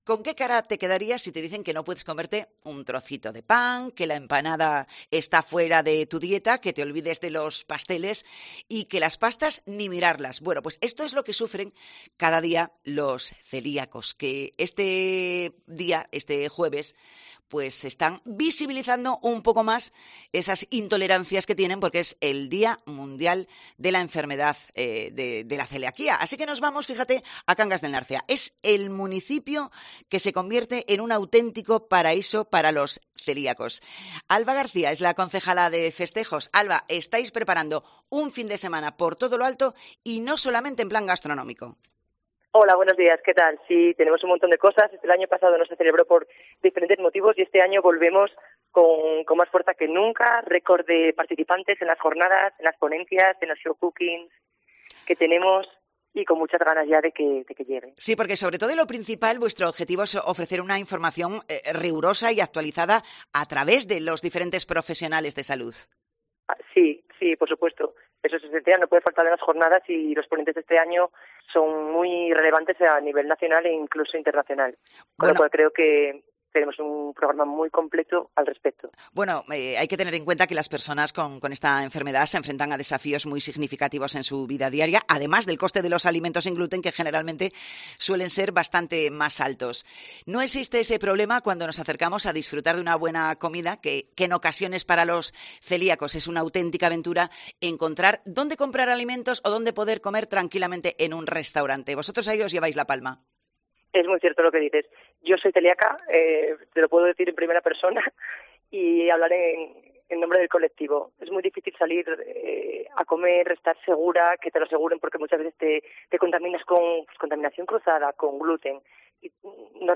Entrevista a la concejala de Festejos de Cangas del Narcea, Alba García